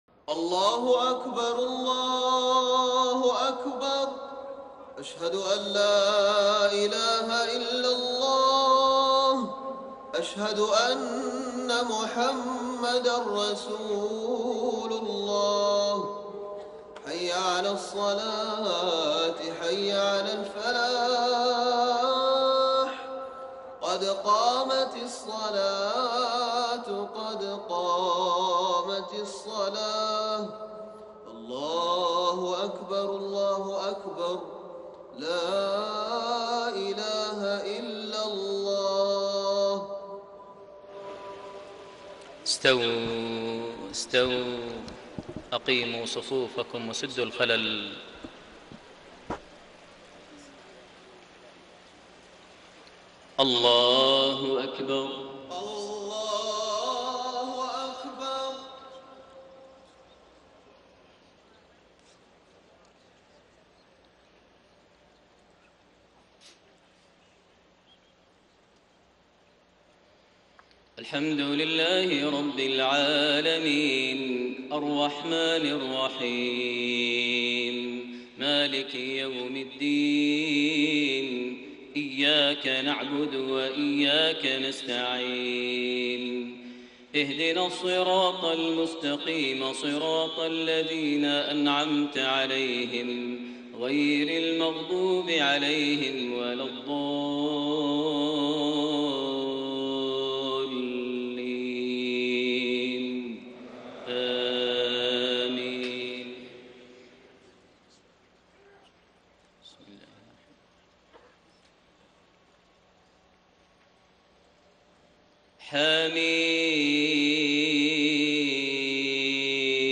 Fajr prayer from Surah Ghaafir > 1433 H > Prayers - Maher Almuaiqly Recitations